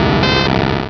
Cri de Magnéti dans Pokémon Rubis et Saphir.